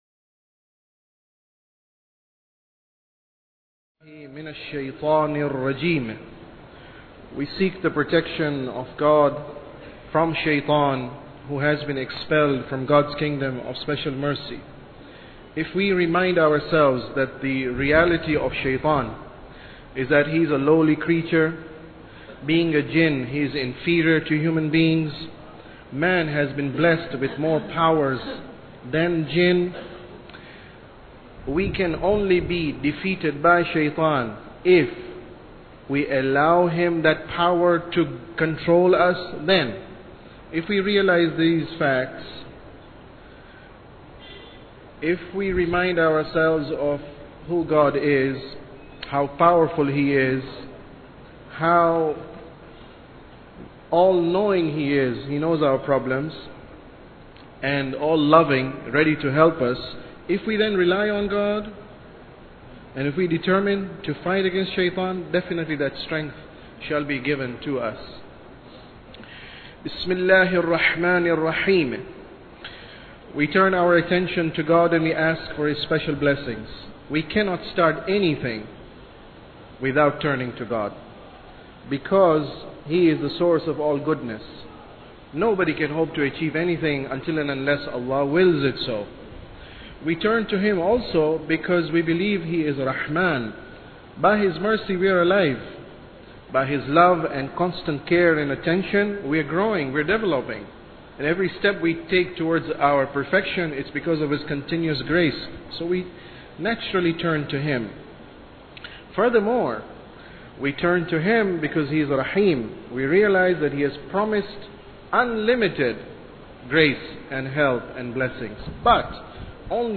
Sermon About Tawheed 15